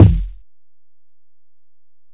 Kick (27).wav